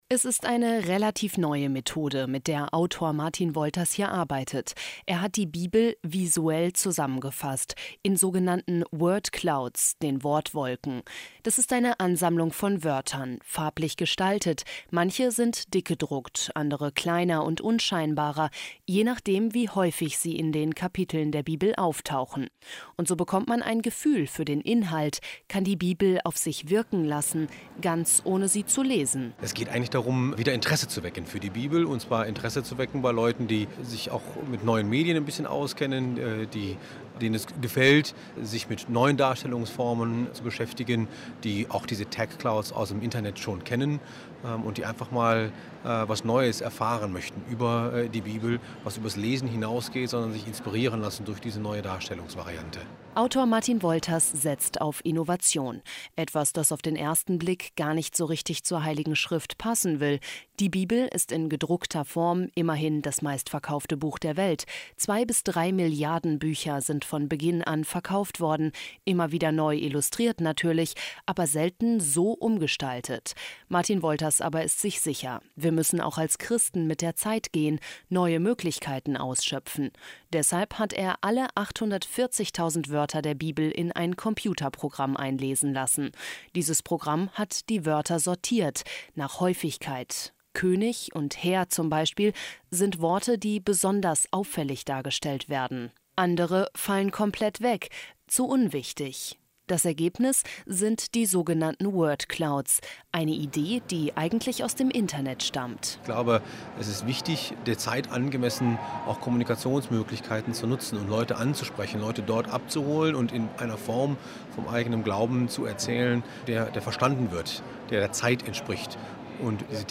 Ein Radiobeitrag im Kölner Domradio (Oktober 2012)